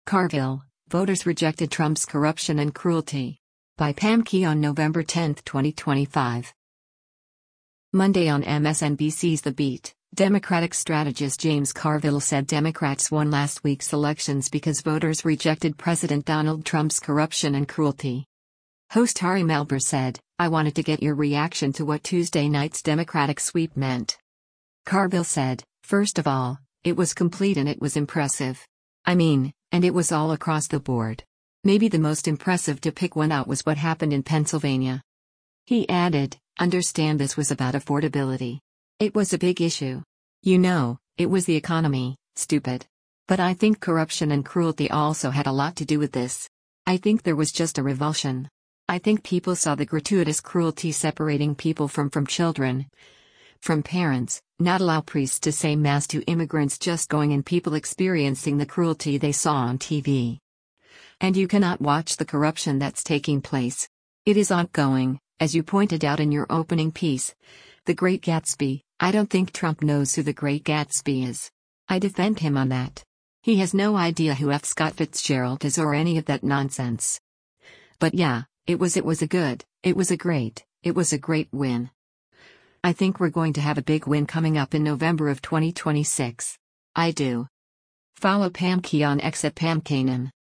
Monday on MSNBC’s “The Beat,” Democratic strategist James Carville said Democrats won last week’s elections because voters rejected President Donald Trump’s “corruption and cruelty.”
Host Ari Melber said, “I wanted to get your reaction to what Tuesday night’s Democratic sweep meant.”